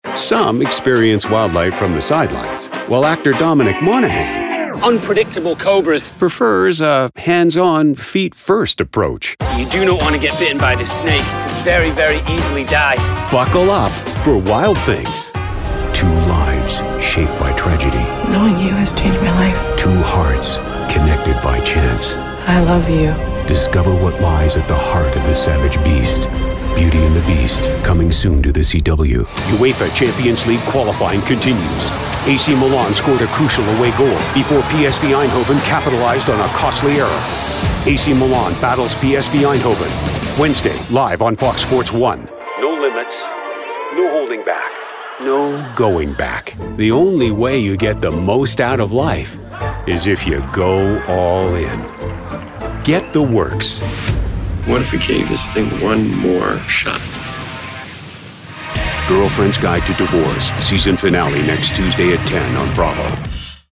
Male
Adult (30-50), Older Sound (50+)
Television Promos
Words that describe my voice are Gravitas, Authority, Mature.
All our voice actors have professional broadcast quality recording studios.